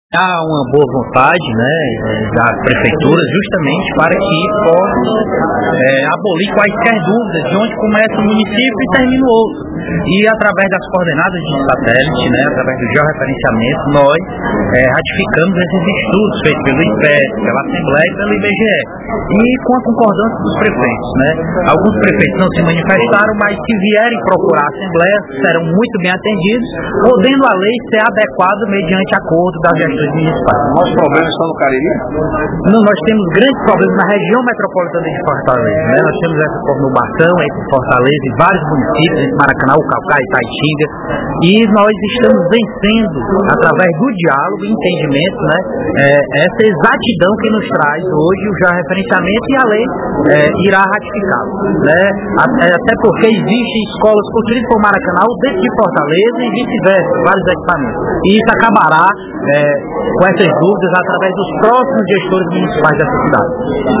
O deputado Julinho (PDT) explicou, durante o primeiro expediente da sessão plenária desta quarta-feira (12/04), a questão dos limites intermunicipais entre os municípios de Juazeiro do Norte e Barbalha e entre Juazeiro do Norte e Crato.